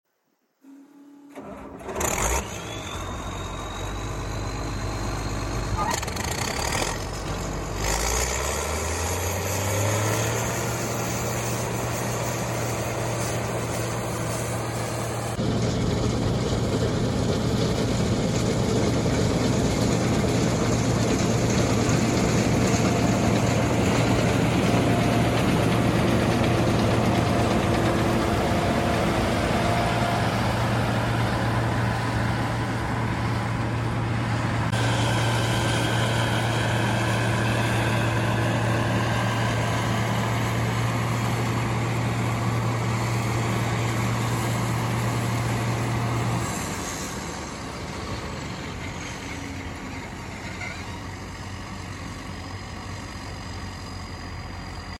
raw 8.3 cummins sound from sound effects free download
raw 8.3 cummins sound from the 2166